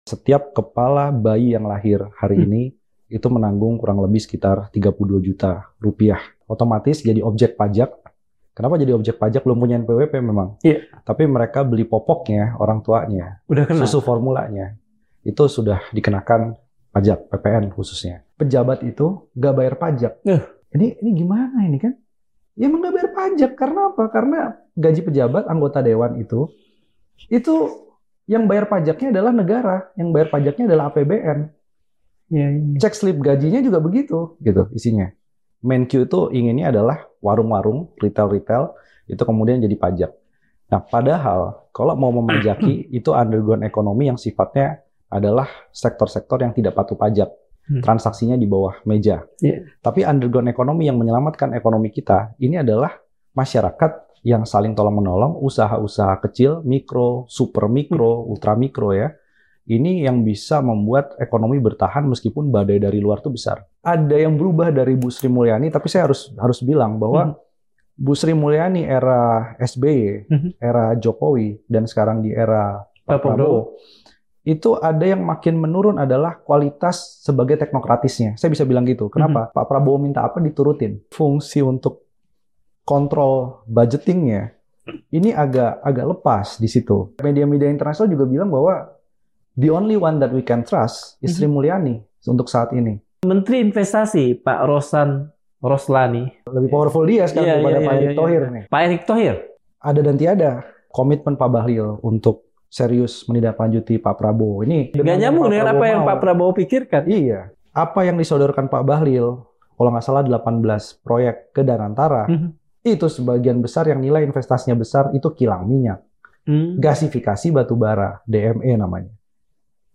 Sementara, menteri-menteri dan pejabat pemerintahan Presiden Prabowo marak dikritik karena kinerjanya. Podcast Madilog Forum Keadilan berikut ini mengkritisi kebijakan ekonomi pemerintahan Prabowo bersama narasumber terpercaya.